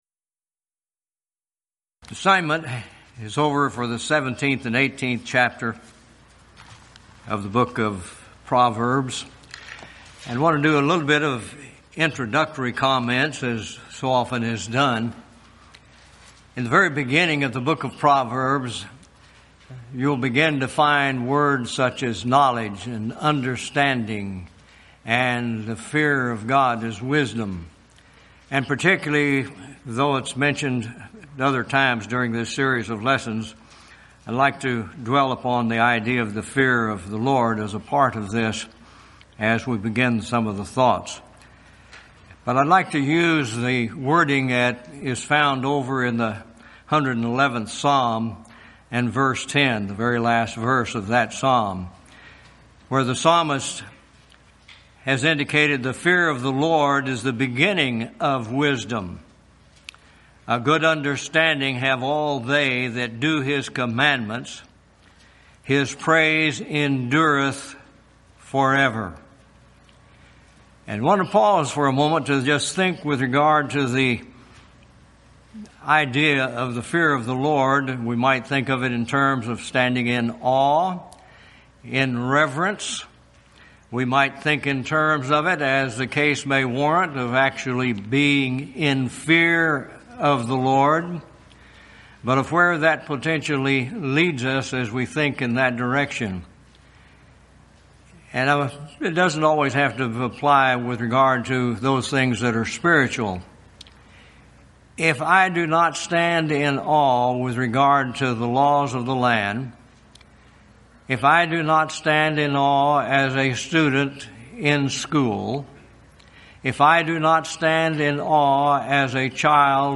Event: 13th Annual Schertz Lectures Theme/Title: Studies in Proverbs, Ecclesiastes, & Song of Solomon
lecture